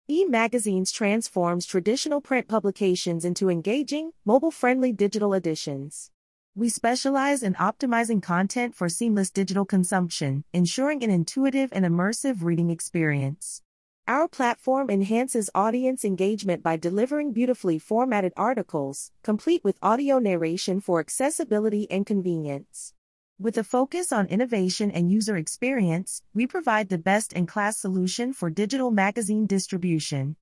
We turn text into lifelike speech by leveraging Neural Text-to-Speech systems to create natural-sounding human audio that keeps your subscribers engaged.